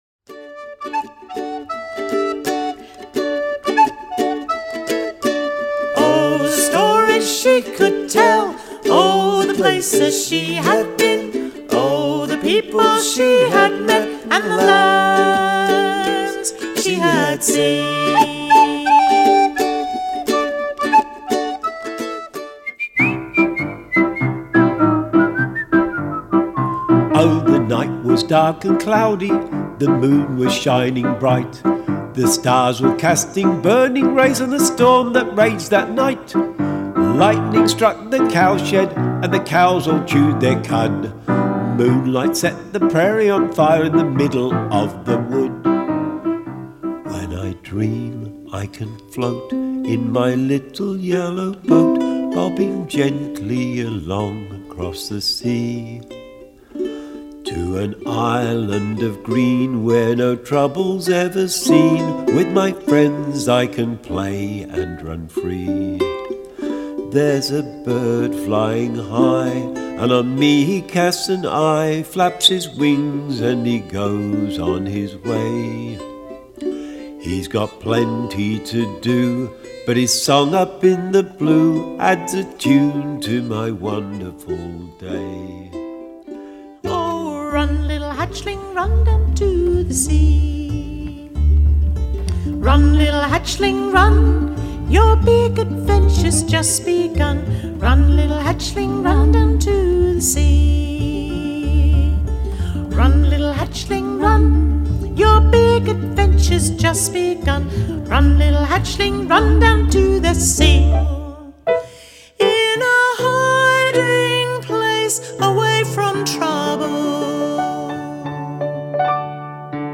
original and traditional songs and dances for young children
It will keep you smiling and your toes tapping.